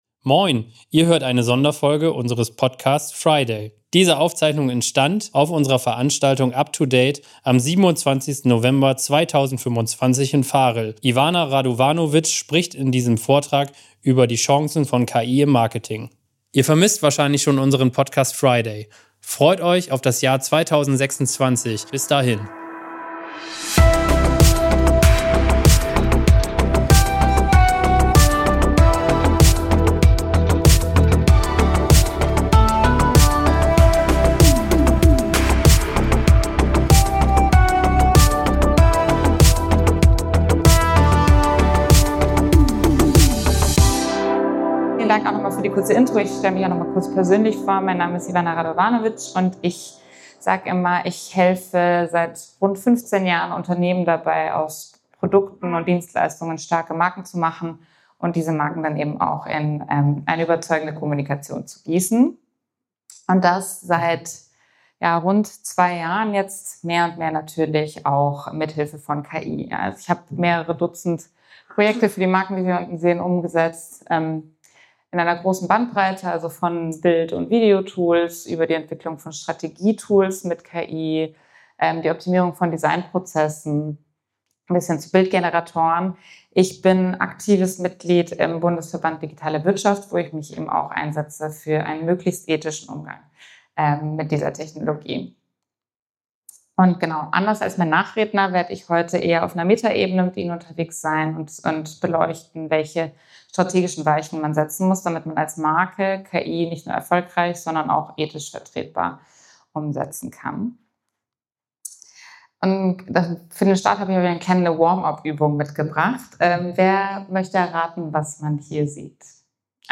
KI eröffnet enorme Hebel für Marke, Marketing und Vertrieb, birgt aber ebenso reale Risiken – von Effizienzblindheit bis Vertrauensverlust. Diese Keynote zeigt, wie verantwortungsvolle Innovation gelingt, durch die Balance aus Ethik, Governance und Experimentierfreude. Entscheidend sind strategische Grundlagen und ein sauberes Setup mit klaren Zielen und Use Cases.